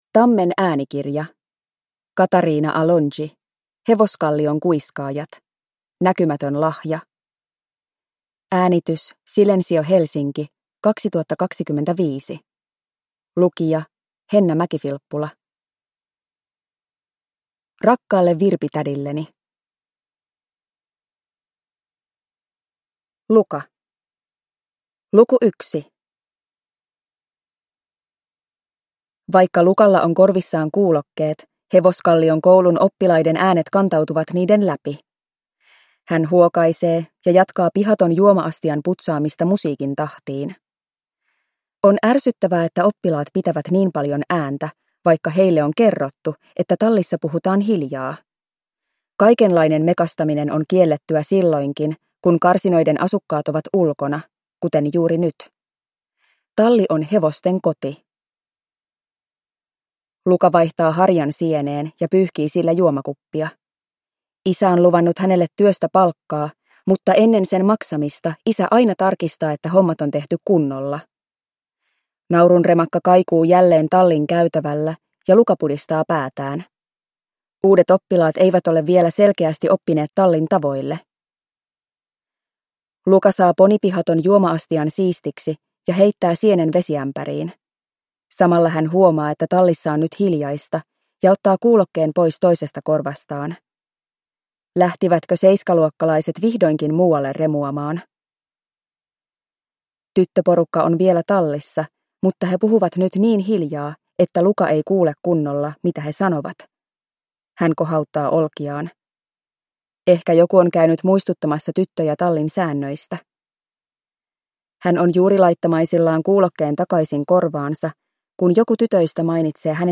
Hevoskallion kuiskaajat 2. Näkymätön lahja (ljudbok) av Katariina Alongi